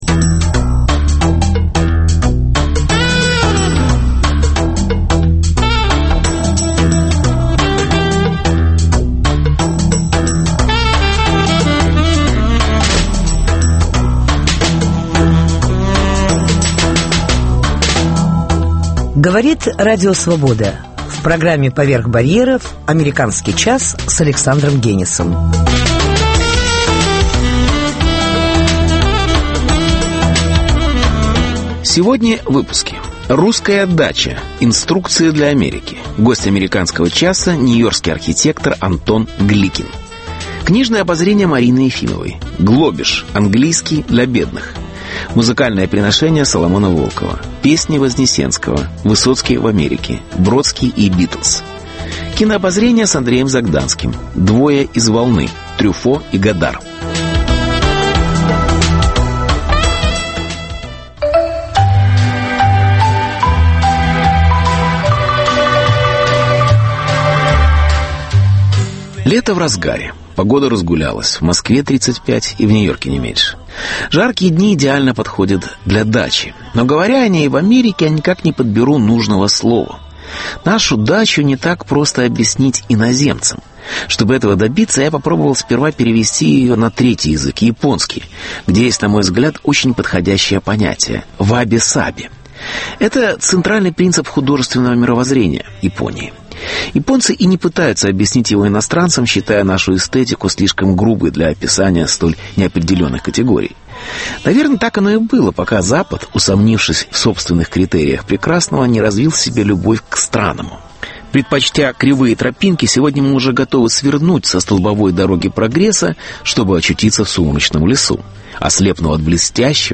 Русская дача: инструкции для Америки. Гость АЧ – нью-йоркский архитектор